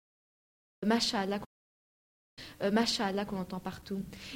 Welkom Over dit project Woordenboek Radio Ontmoetingen Creations Rugzak Contact Het Boek Masha'Allah download ❧ uitspraak ❧ voorbeeld Masha'Allah qu'on entend partout.